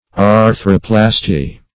Meaning of arthroplasty. arthroplasty synonyms, pronunciation, spelling and more from Free Dictionary.